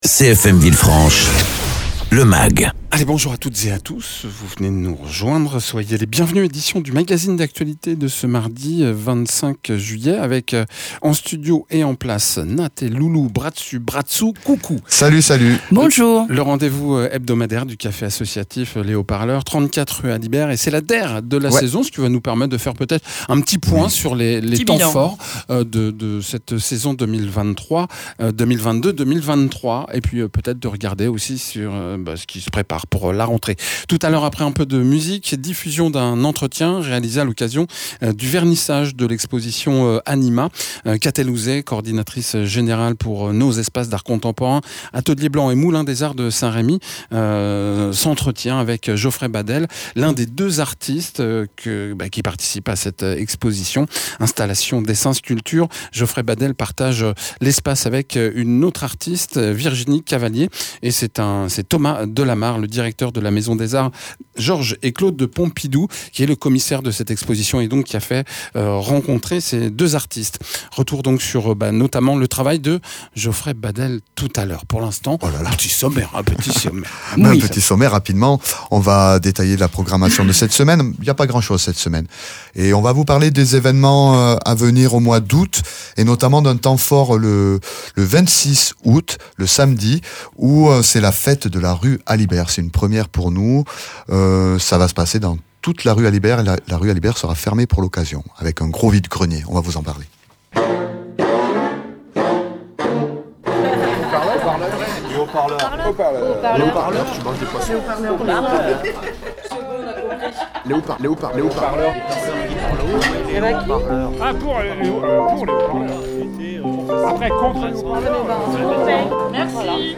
Le programme des animations de la semaine et à venir aux Hauts Parleurs, 34 rue Alibert. Egalement dans ce mag, reportage consacré à l’exposition « Anima » actuellement visible et jusqu’au 3 septembre à l’Atelier Blanc et au Moulin des arts de St Rémy.
Mags